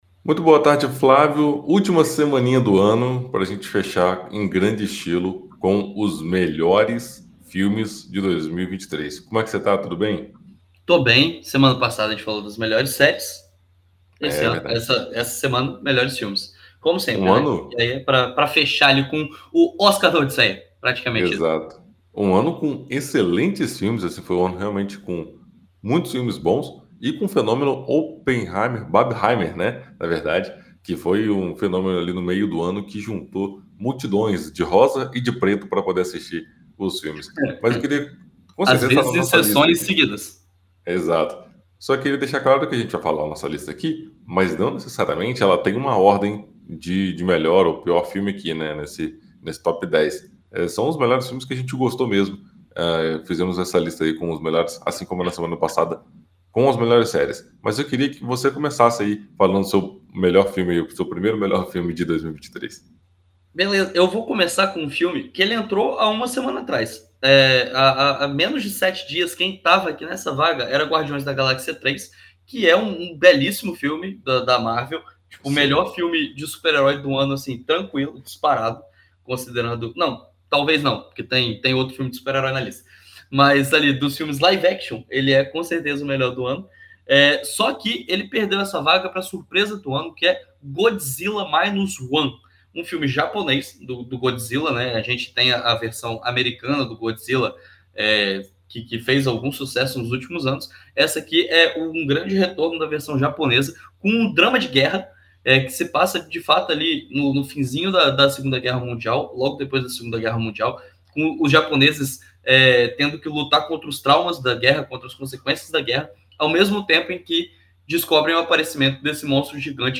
Na coluna “Sem Spoiler” desta quinta-feira (28) na BandNews FM Espírito Santo,